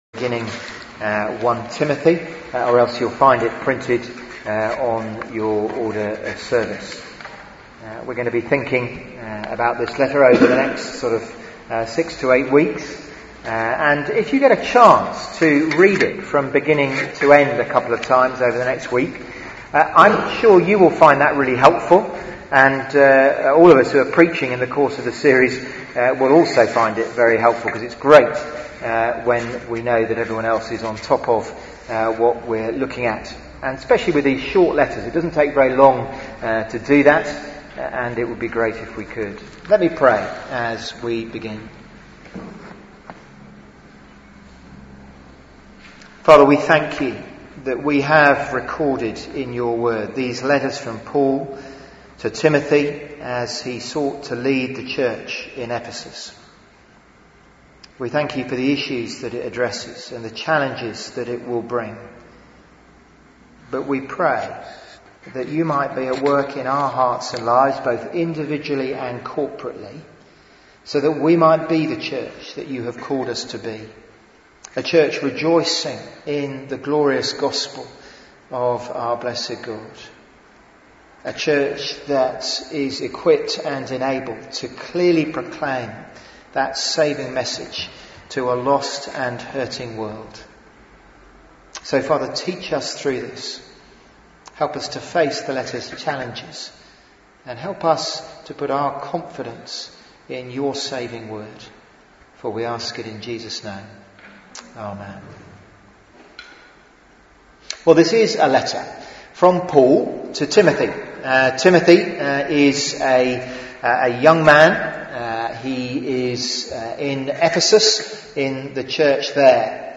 Media for 11am Service
Theme: Guard the gospel Sermon